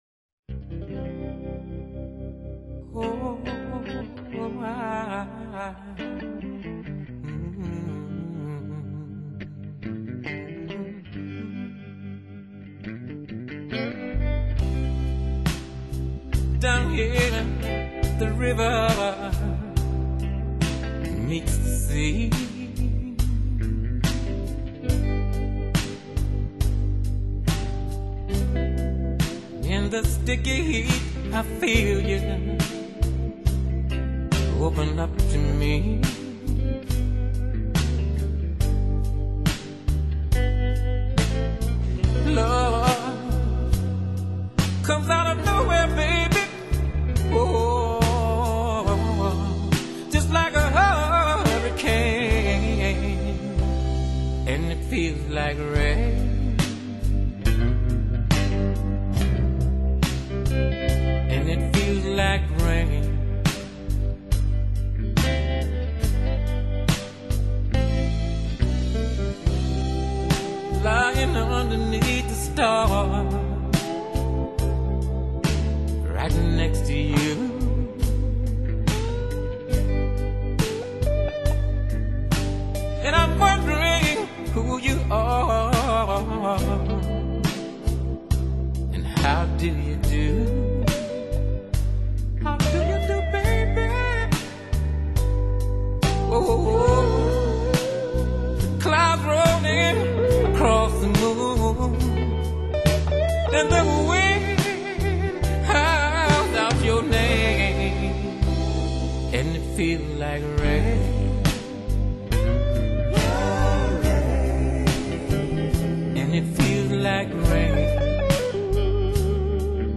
高歌时那把浑然天成的嗓子，听似漫不经心，却令人神往
全为一流抒情歌曲。录音勿须怀疑，暖暖的音色之中，乐器结像定位清晰